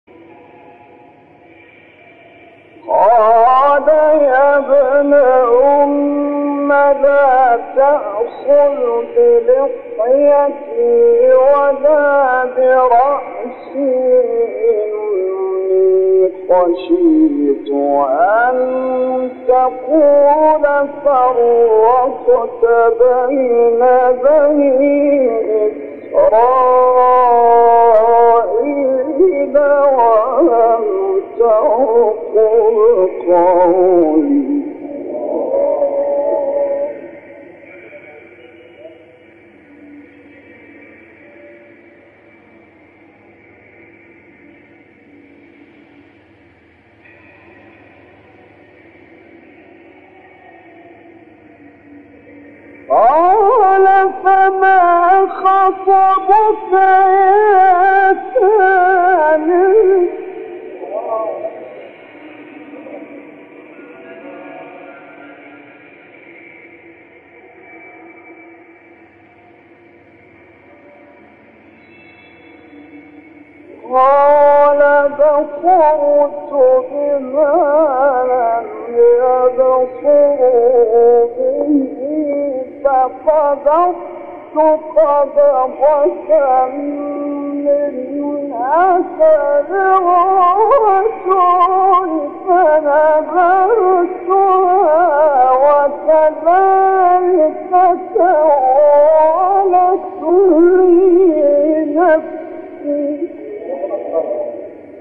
گروه شبکه اجتماعی: فرازهایی از تلاوت قاریان بنام کشور مصر ار جمله شیخ رفعت، احمد صالح، عبدالفتاح شعشاعی را می‌شنوید.